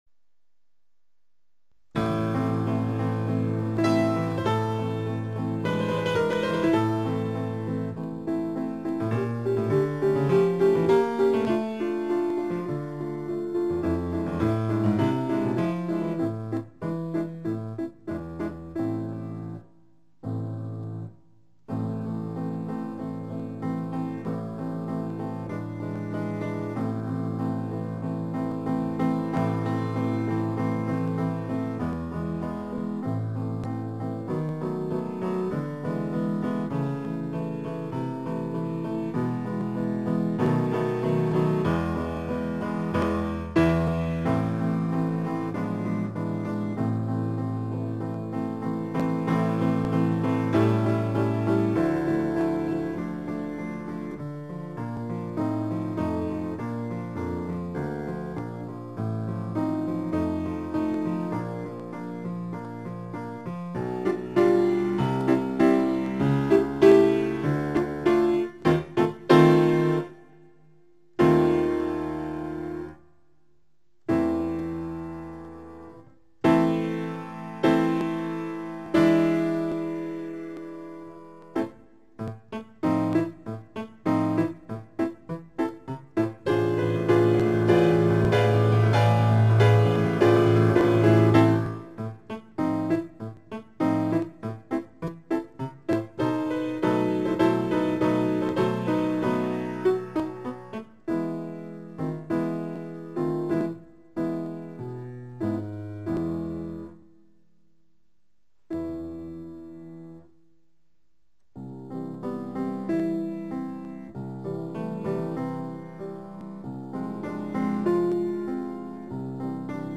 小提琴